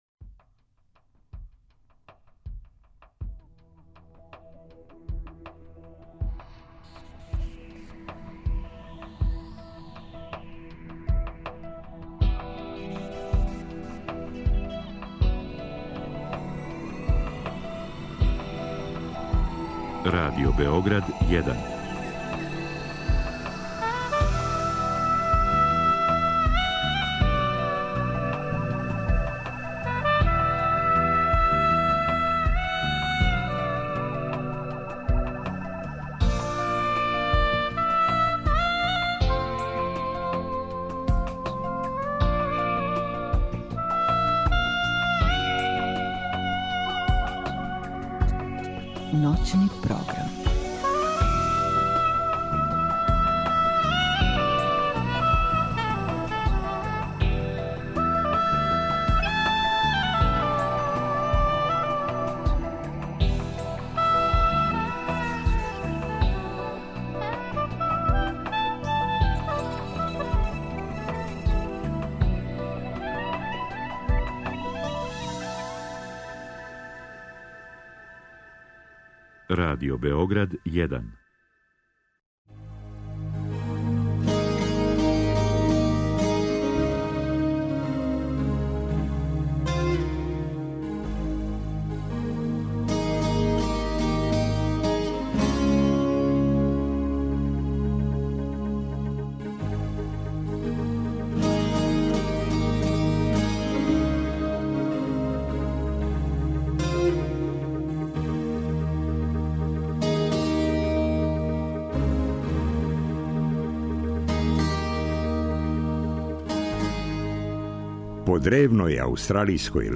У Сновреме стижу људи који су учествовали у организацији 9. ФИСТ-а. Разговарамо о представама, радионицама, предавањима, пројекцијама, радију... о фестивалским ђаконијама које су и ове године припремили за све заинтересоване.